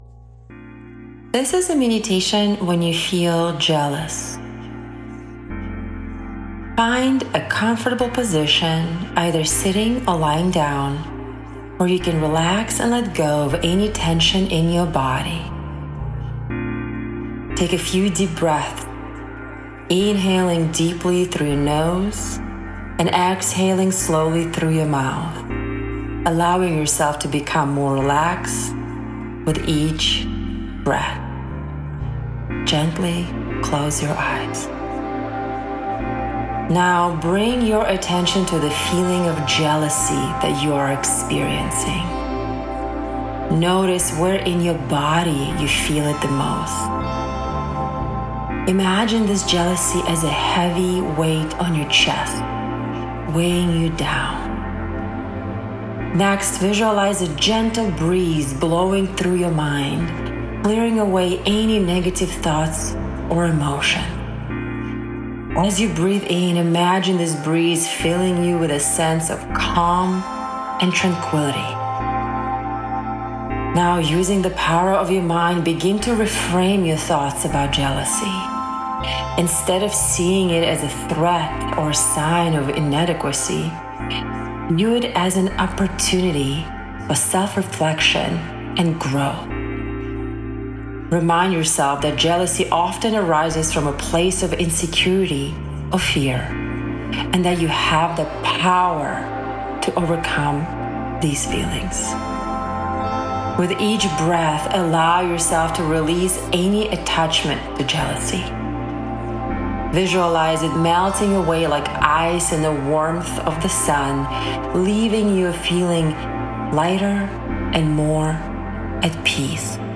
Once recorded, our musicians infuse the mini with unique, powerful music, capturing its essence.